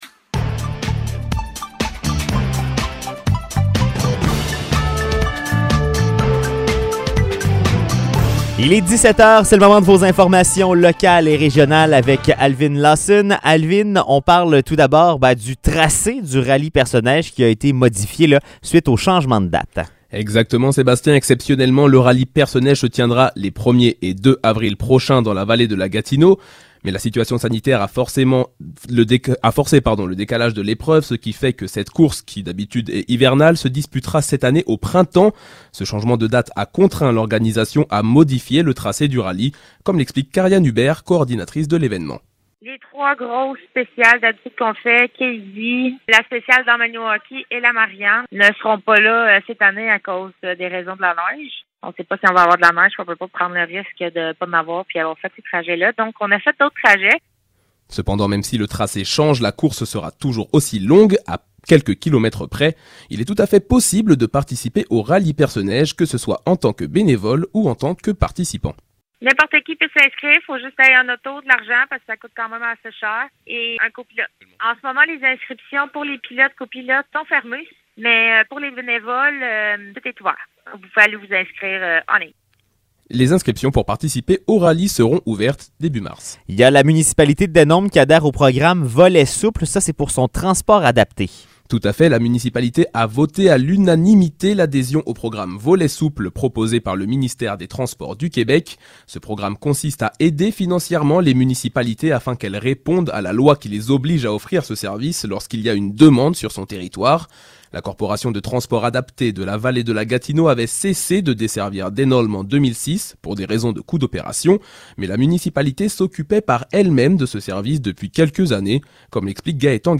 Nouvelles locales - 2 février 2022 - 17 h